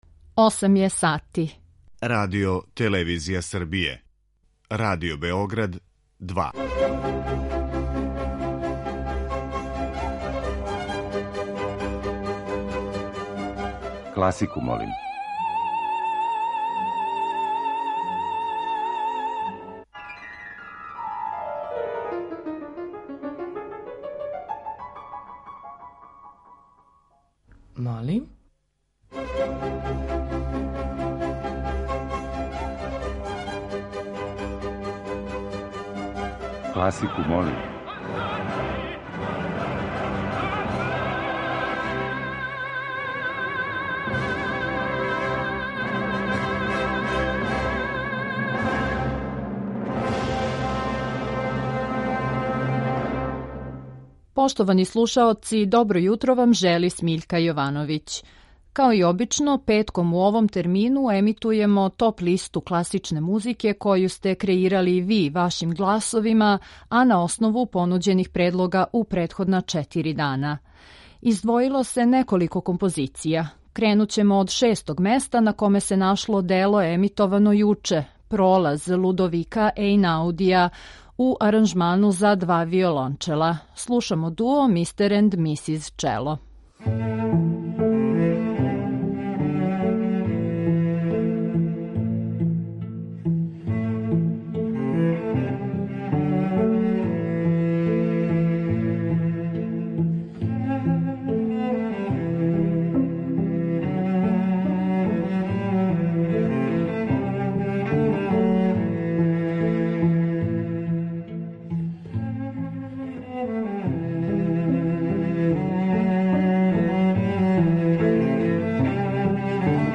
Топ-листа озбиљне музике, по избору слушалаца.